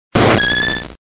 Cri de Yanma dans Pokémon Diamant et Perle.